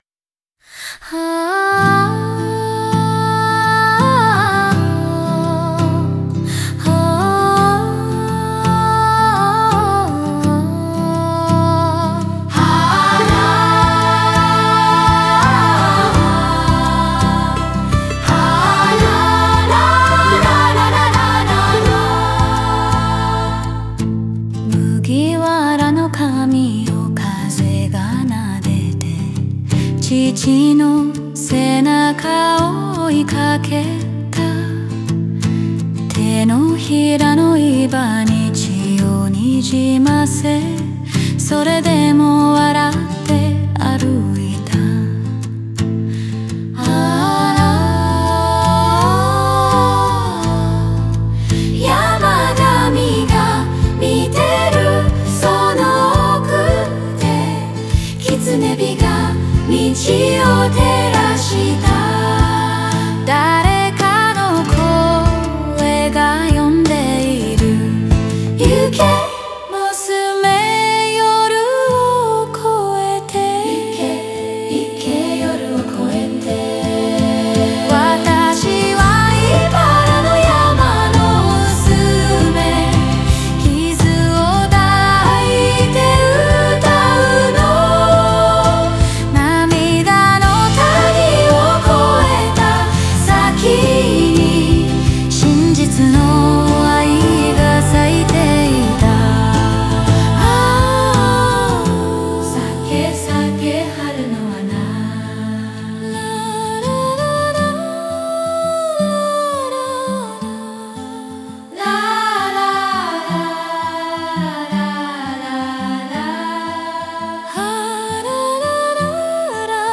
少年少女の透明なコーラスは、彼女を見守る山の精霊や村の子どもたちの声のように響き、孤独な旅路に温もりを添える。